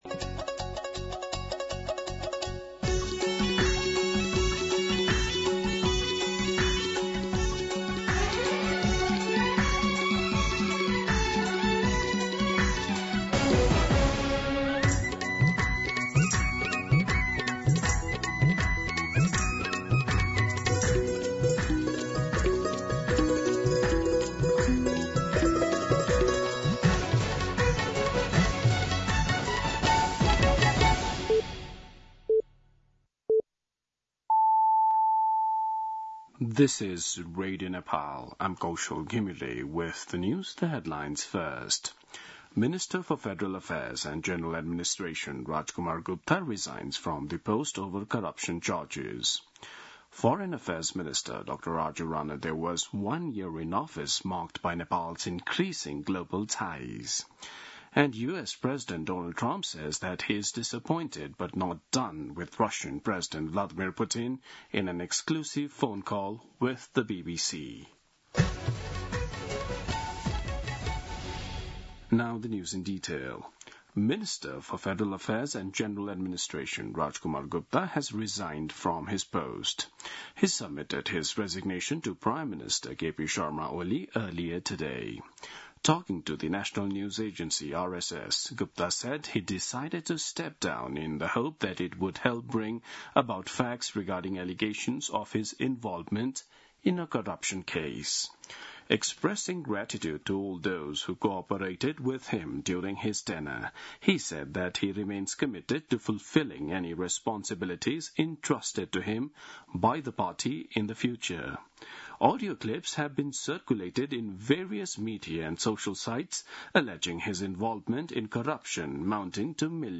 दिउँसो २ बजेको अङ्ग्रेजी समाचार : ३१ असार , २०८२
2pm-English-News-31.mp3